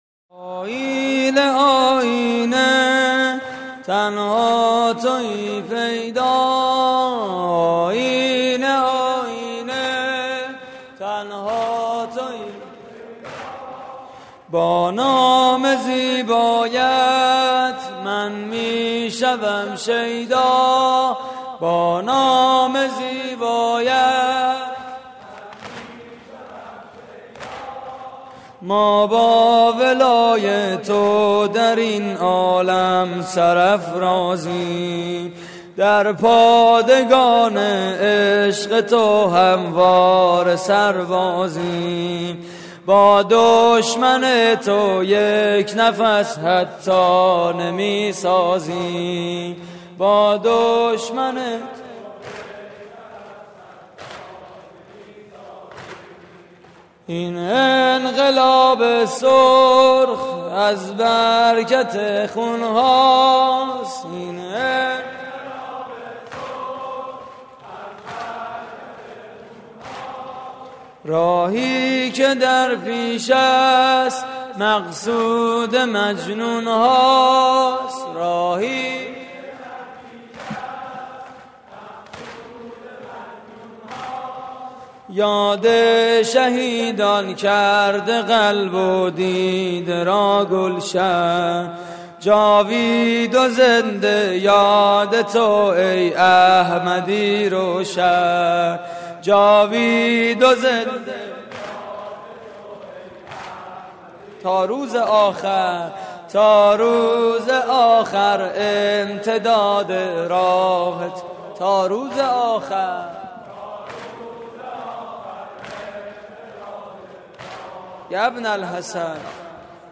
شور: تنها تویی پیدا متاسفانه مرورگر شما، قابیلت پخش فایل های صوتی تصویری را در قالب HTML5 دارا نمی باشد.
مراسم عزاداری شهادت امام سجاد (ع)